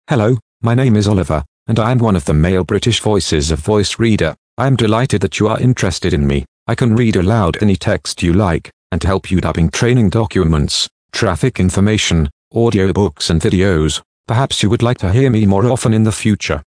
Voice Reader Home 22 English (British) - Male voice [Oliver]
Voice Reader Home 22 ist die Sprachausgabe, mit verbesserten, verblüffend natürlich klingenden Stimmen für private Anwender.